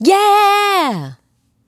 Yeah-G#.wav